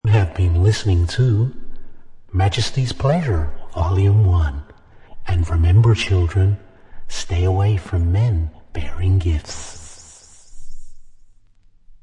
slightly creepy message
Disco House Funk